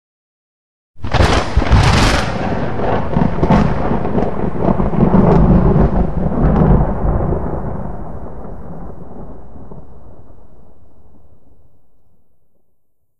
thundernew6.ogg